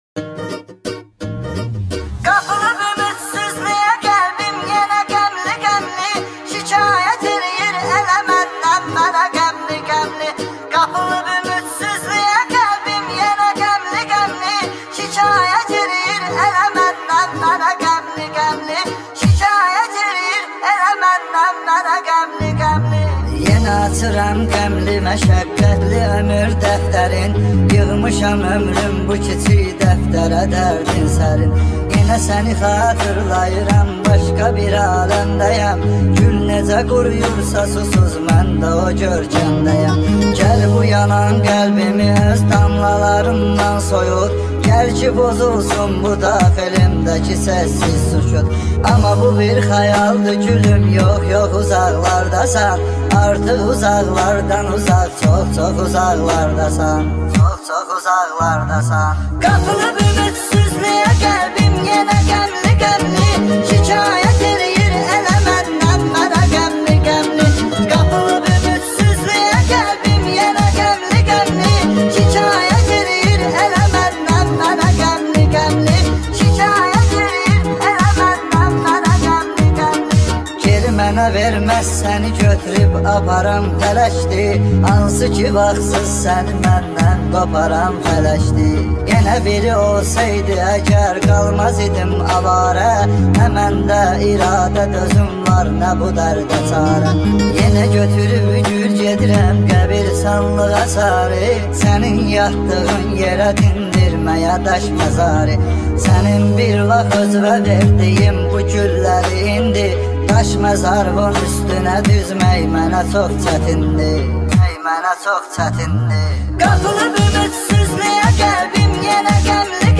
آهنگ ترکی
با صدای بچه